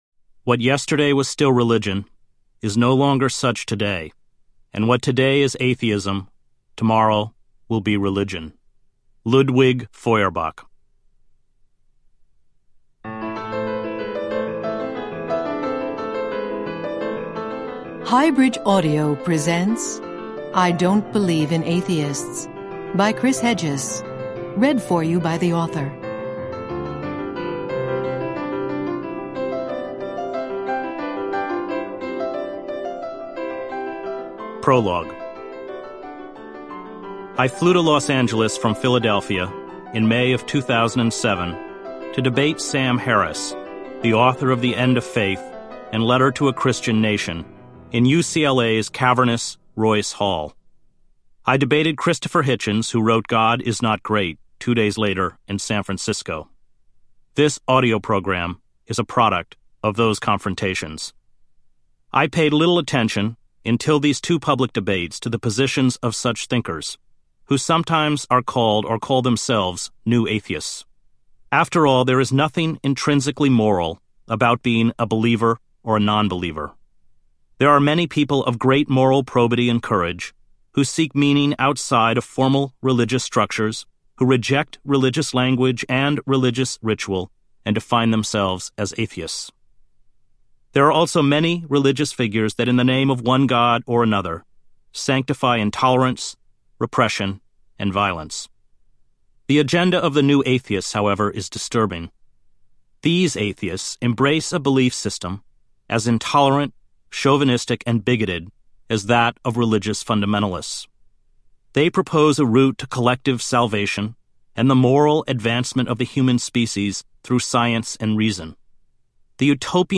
I Don’t Believe in Atheists Audiobook
Narrator